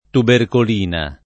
[ tuberkol & na ]